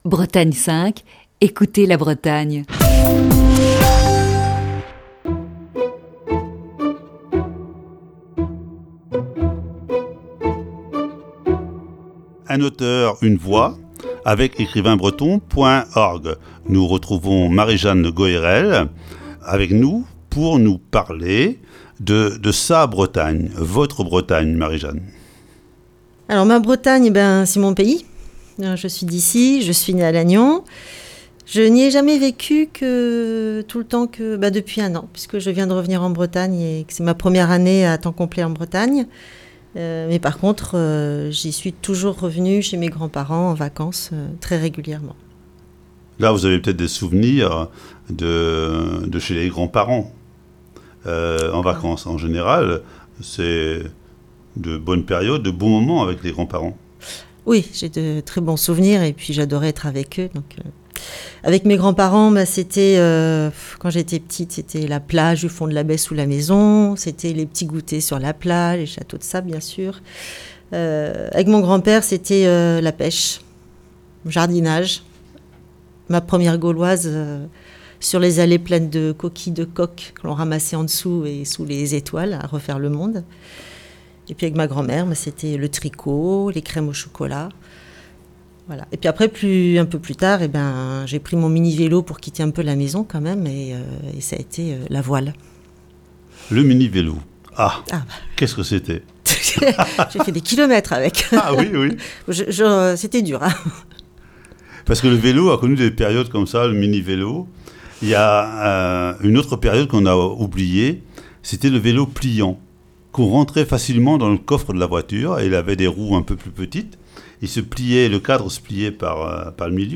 Aujourd'hui, deuxième partie de cet entretien.